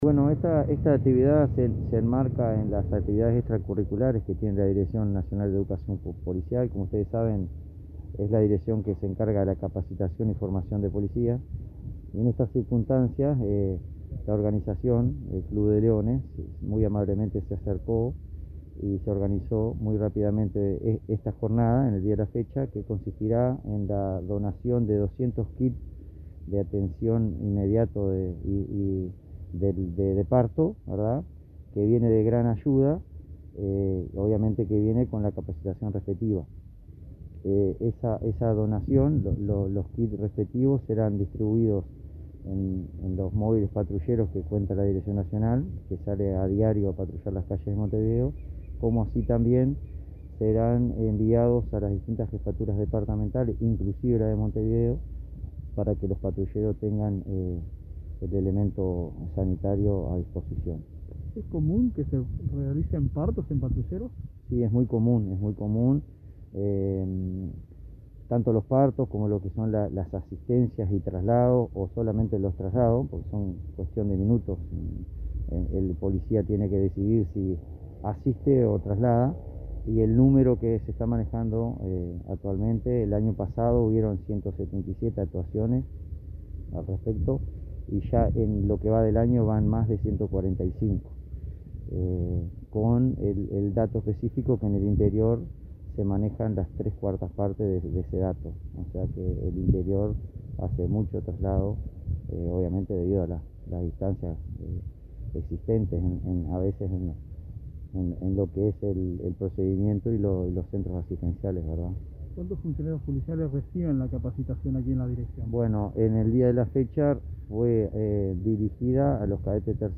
Entrevista al encargado de la Dirección Nacional de Educación Policial, Efraín Abreu